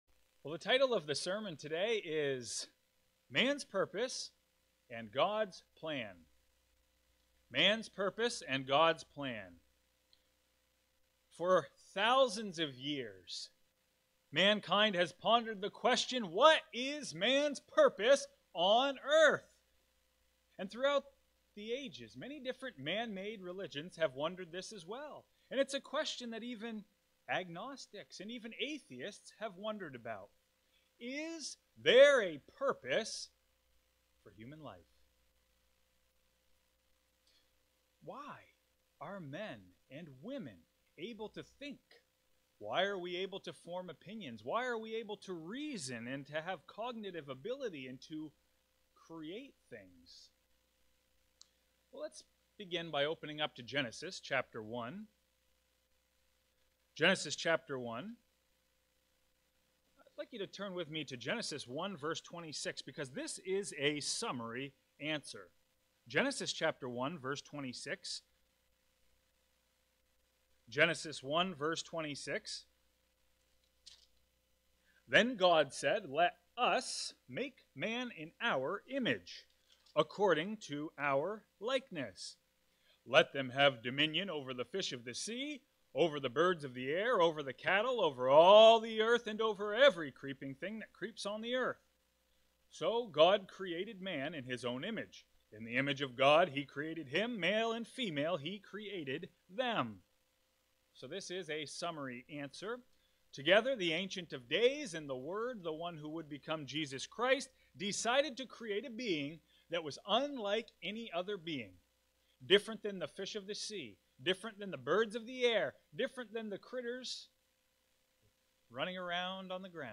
Sermons
Given in Bismarck, ND Fargo, ND Minot, ND